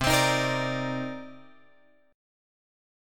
C Minor 9th